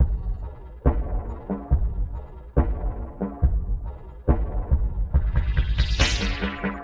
鼓的循环 6
描述：循环处理缓慢